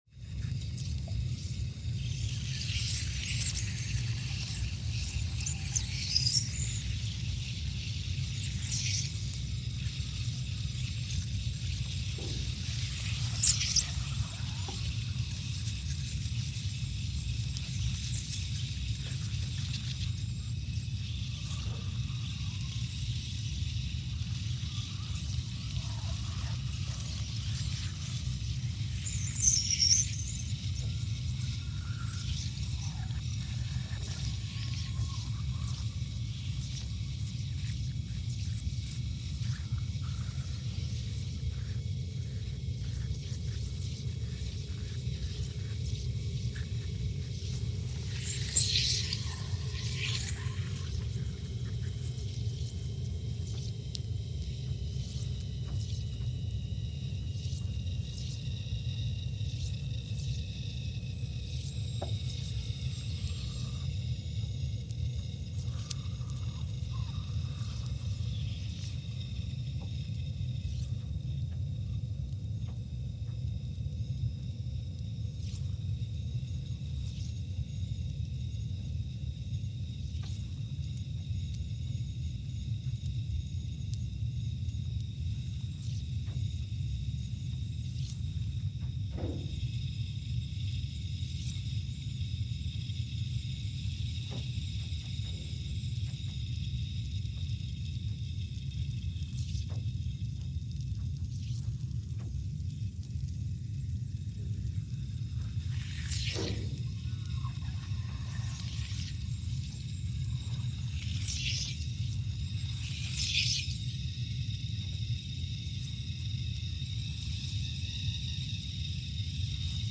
Scott Base, Antarctica (seismic) archived on November 8, 2019
Sensor : CMG3-T
Speedup : ×500 (transposed up about 9 octaves)
Loop duration (audio) : 05:45 (stereo)
SoX post-processing : highpass -2 90 highpass -2 90